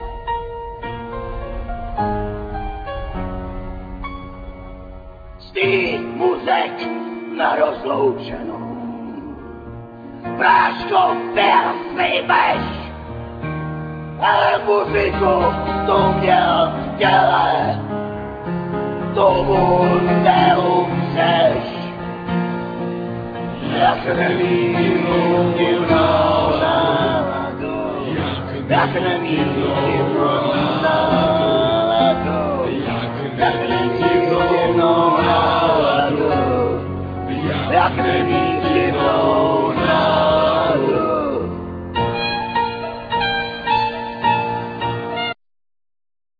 Vocal,Trumpet,Tuboflaut,Backing vocal
Piano,Keyboard,Cymbals,Backing vocal
Drums,Persussions
Clarinet,Bariton sax,Alt sax,Backing vocal
El.guitar
Double bass